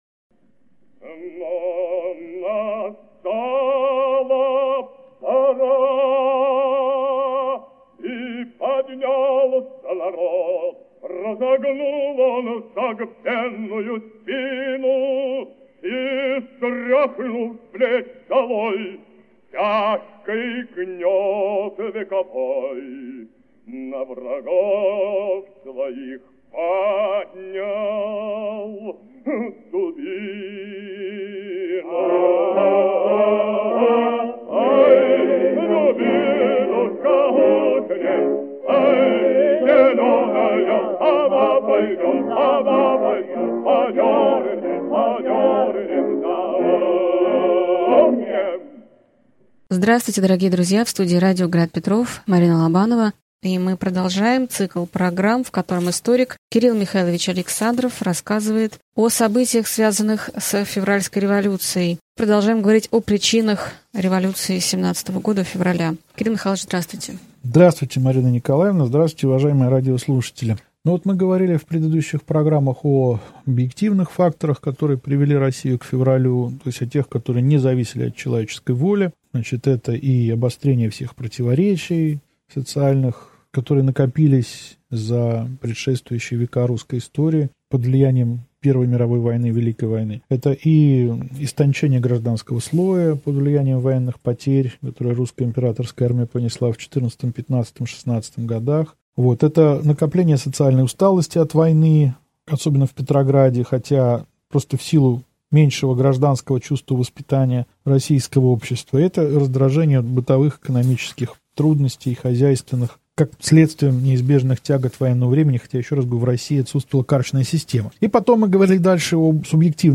Аудиокнига Февральская революция и отречение Николая II. Лекция 7 | Библиотека аудиокниг